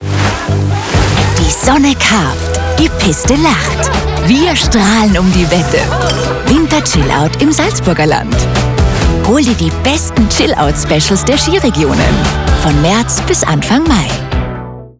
• Hörfunk-Leadkampagne: Über die Sender Kronehit, Ö3, FM4 und RMS Austria Top Kombi werden rund 38 Millionen Bruttokontakte generiert.